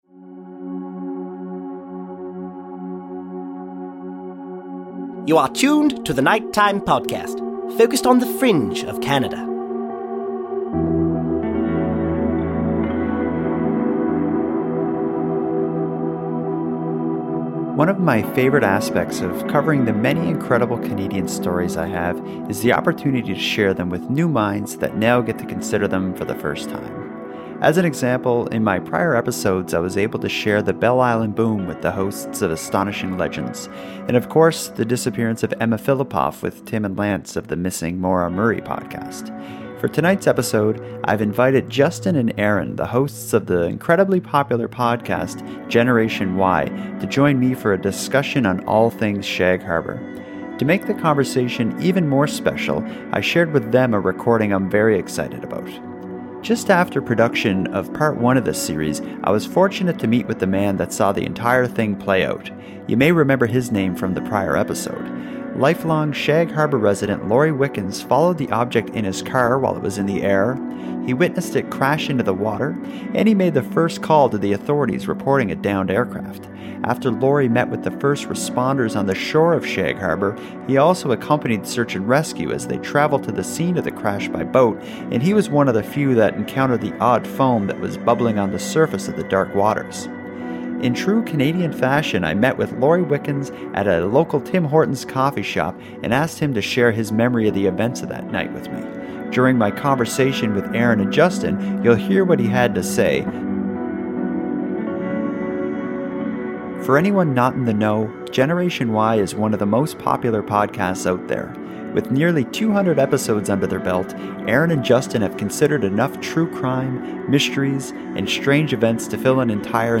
a key Shag Harbour Witness, which I recorded during our recent meeting at a Tim Horton's Coffee Shop.